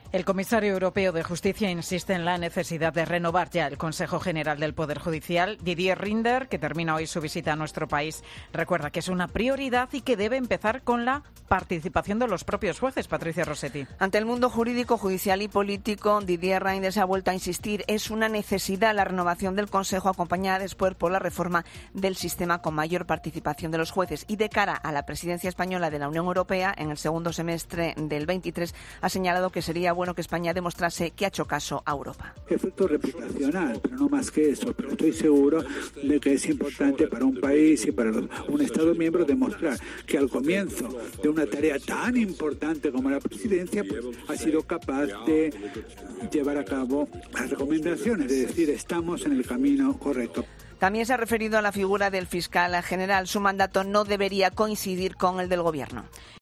El comisario europeo de Justicia aconseja a España renovar el CGPJ cuanto antes. Crónica